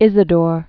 (ĭzĭ-dôr), Saint 560?-636.